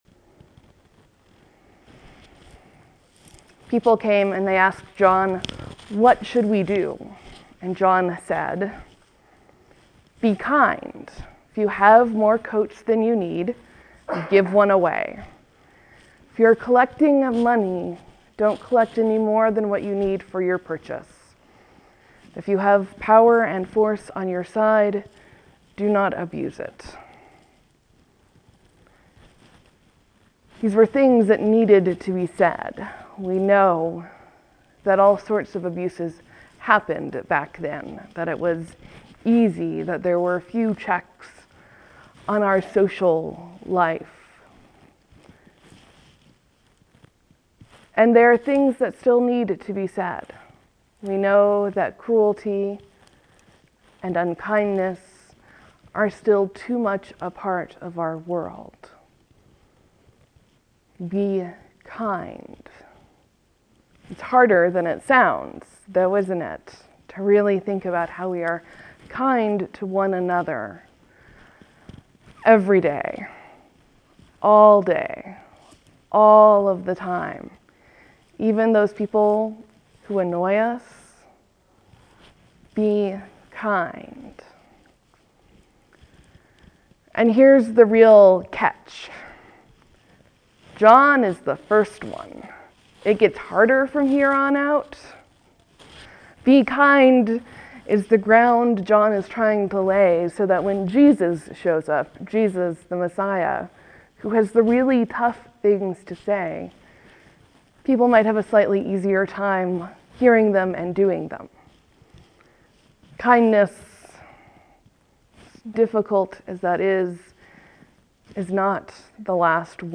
(There will be a few moments of silence before the sermon begins.